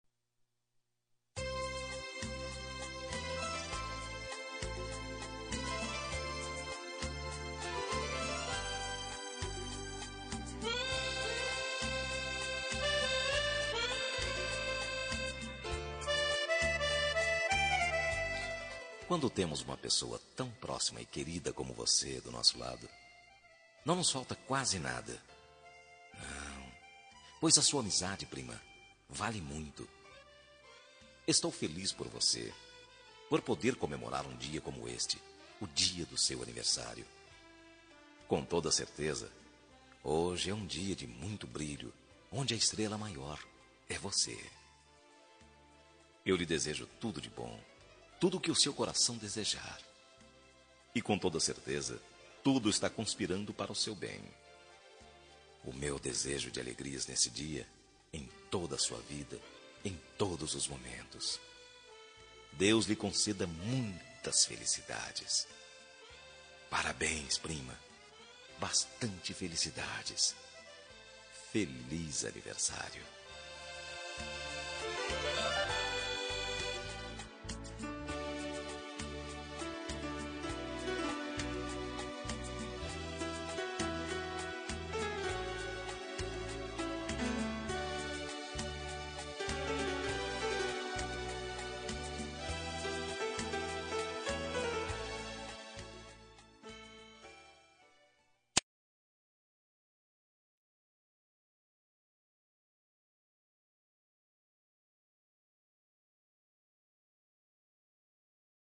Aniversário de Prima – Voz Masculina – Cód: 042819 – Distante